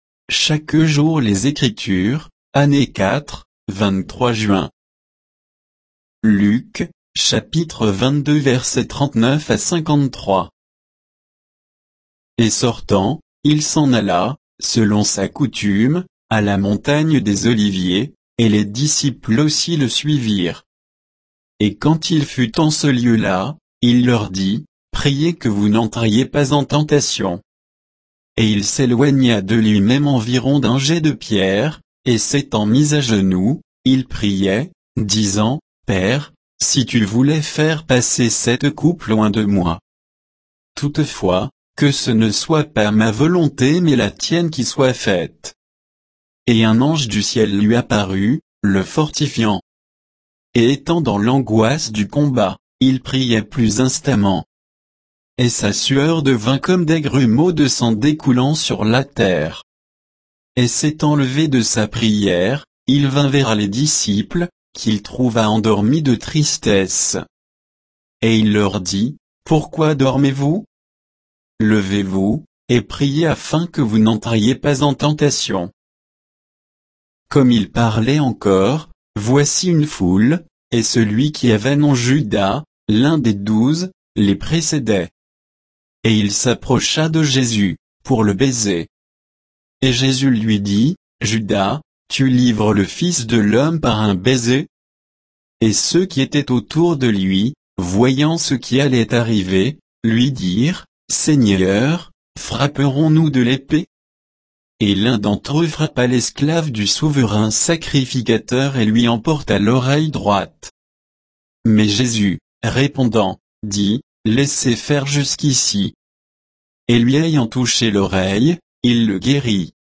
Méditation quoditienne de Chaque jour les Écritures sur Luc 22, 39 à 53